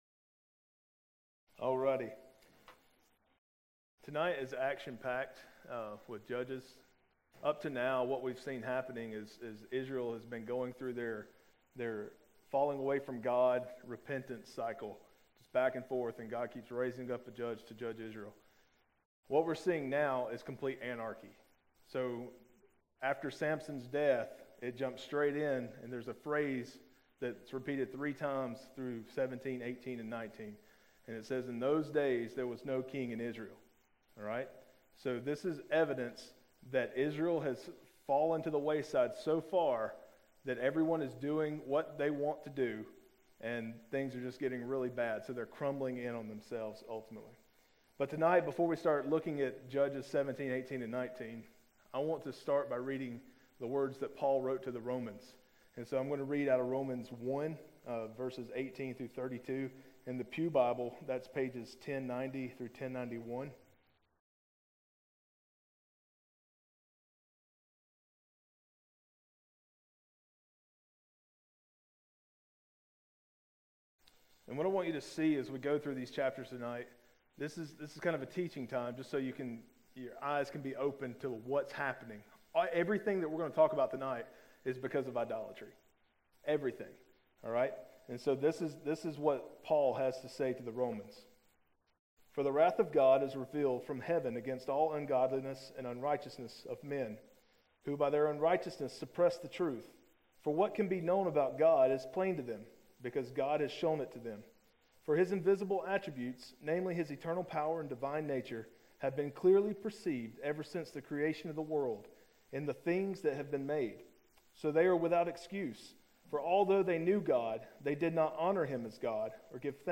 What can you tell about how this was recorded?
April 14, 2013 PM Worship | Vine Street Baptist Church